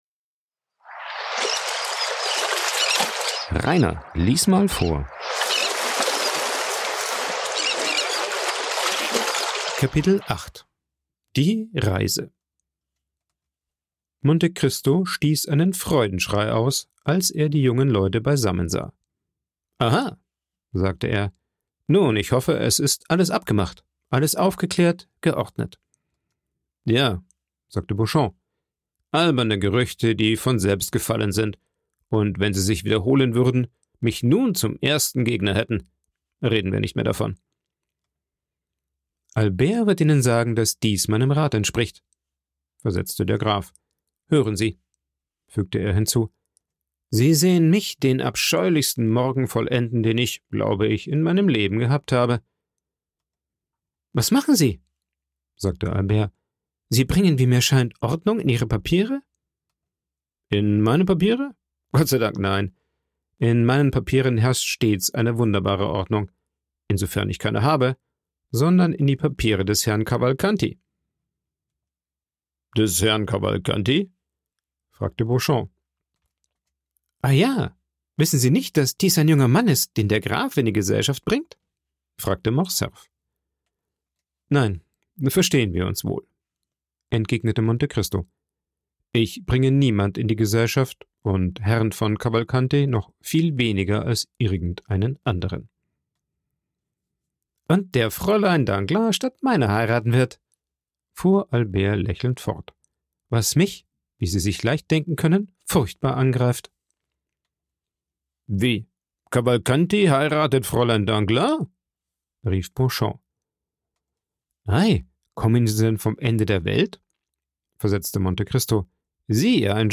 Dieser lädt Albert ein ein paar Tage in der Normandie mit ihm zu verbringen was Albert auch annimmt. 2 Tage nach der Ankunft überbringt ein Bote schlimme Neuigkeiten aus Paris. Vorgelesen
aufgenommen und bearbeitet im Coworking Space Rayaworx, Santanyí, Mallorca.